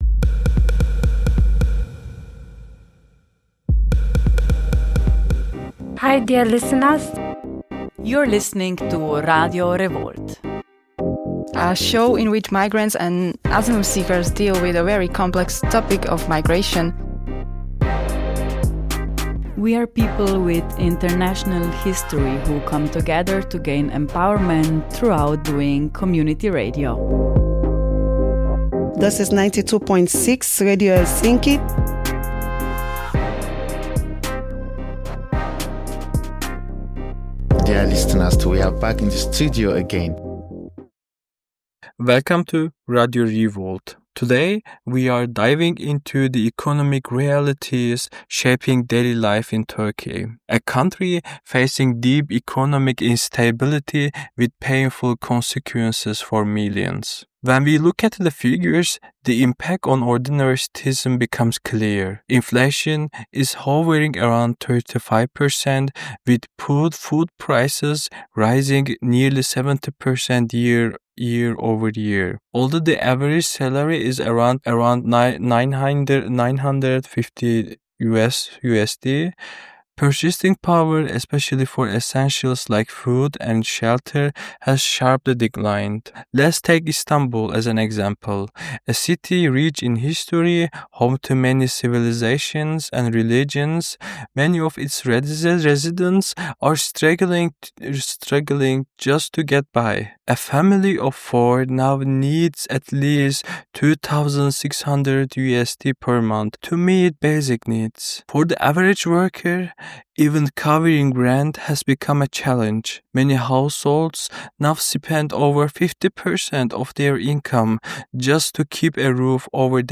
In this episode of Radio Revolt, we explore the economic crisis unfolding in Turkey and its profound impact on daily life. With inflation soaring and purchasing power plummeting, we hear firsthand from Istanbul locals grappling with rising costs and diminishing opportunities.